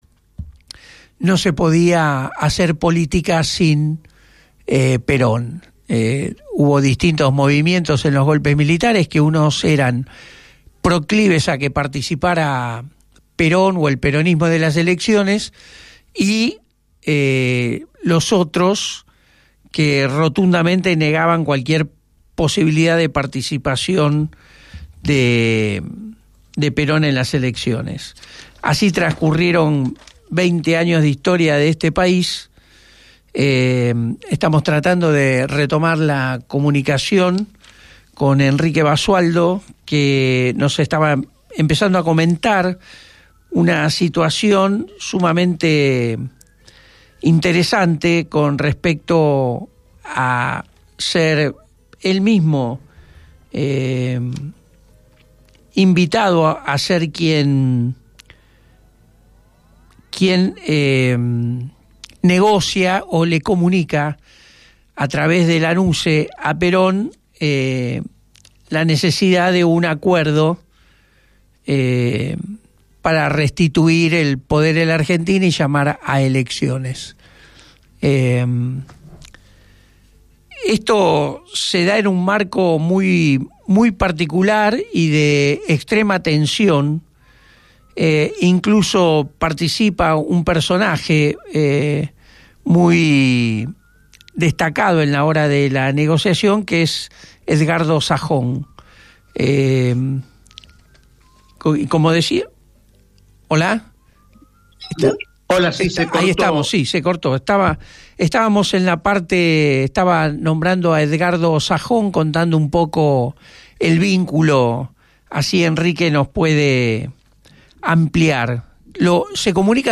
radio Gráfica